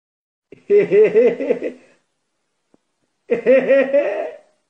Laughing Sound Effects MP3 Download Free - Quick Sounds